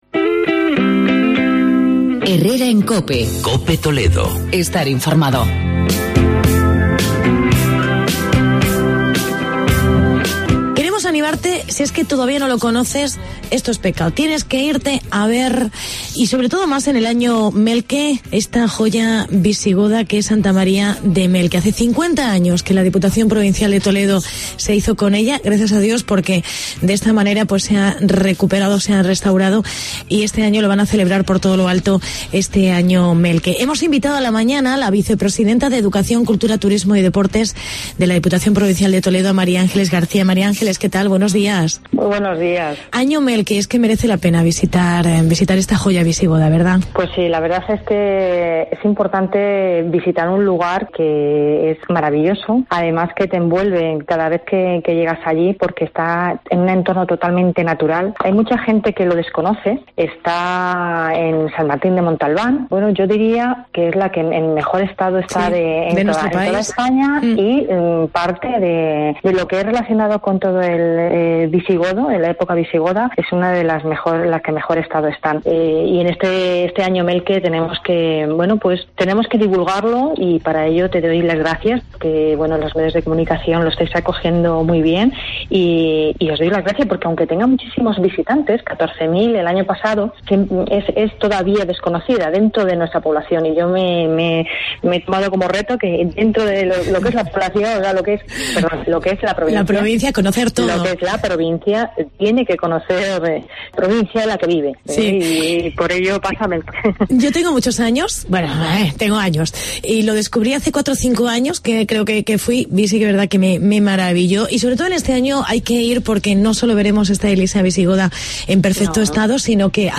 Entrevista a la diputada: Mª Ángeles García